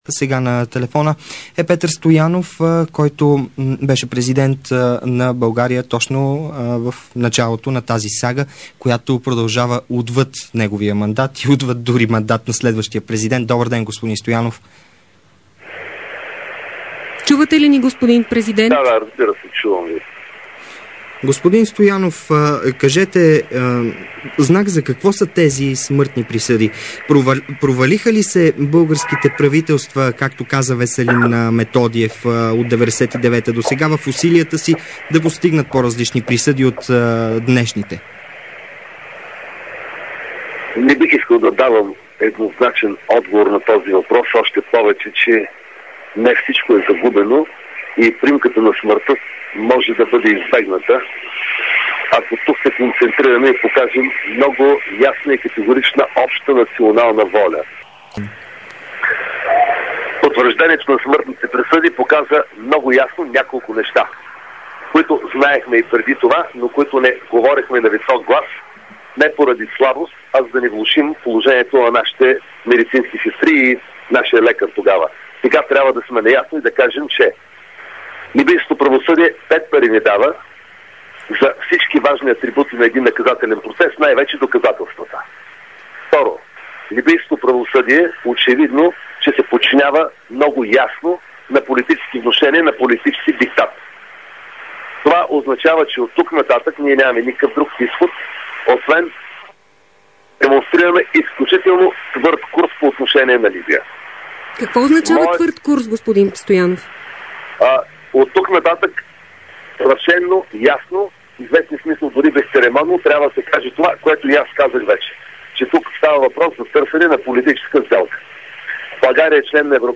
Интервю с Петър Стоянов-19.12.2007